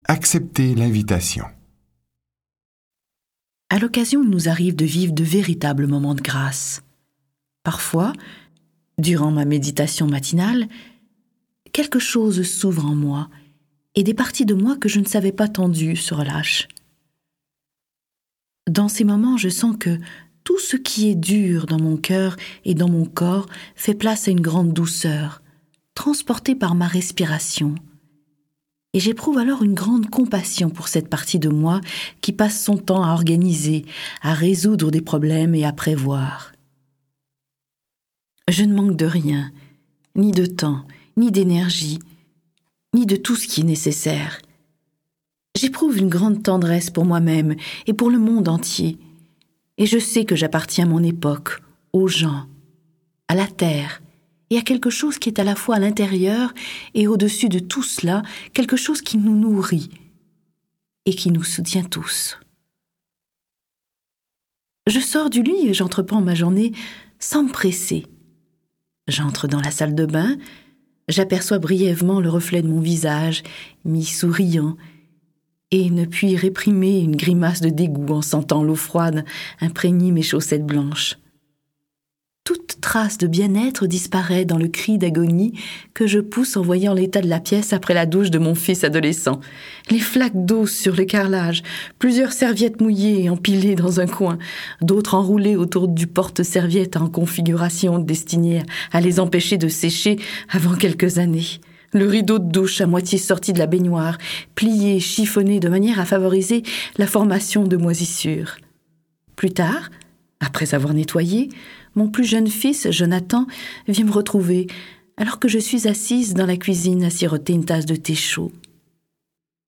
Click for an excerpt - L'invitation de Oriah Mountain Dreamer